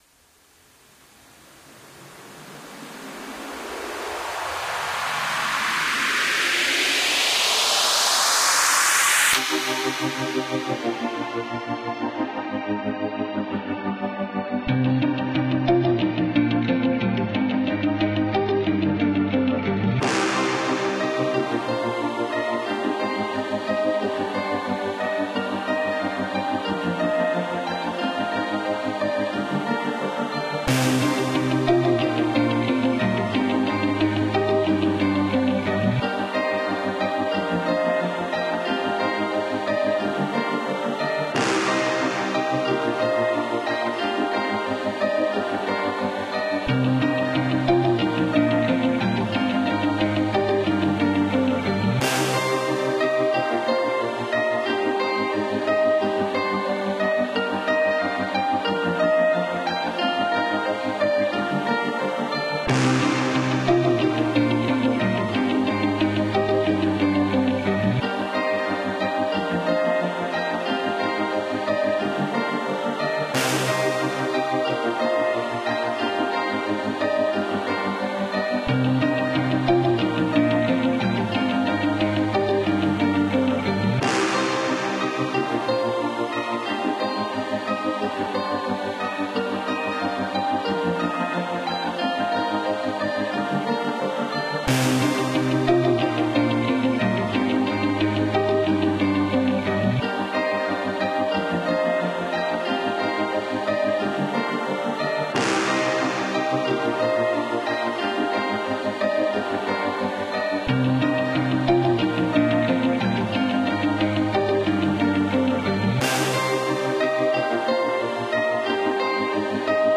A few tracks inspired by the 80s and 90s and retro video games, but a with a modern twist.
ominousadventure.ogg